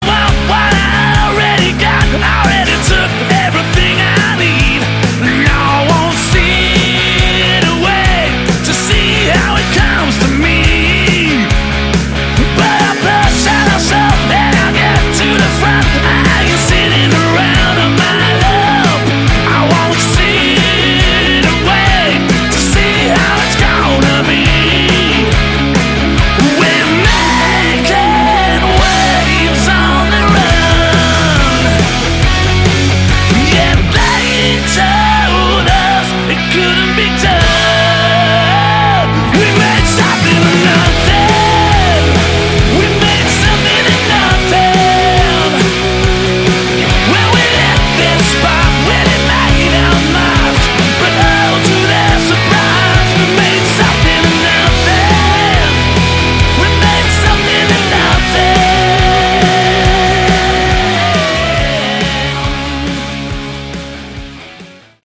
Category: Hard Rock
vocals, bass
guitars
drums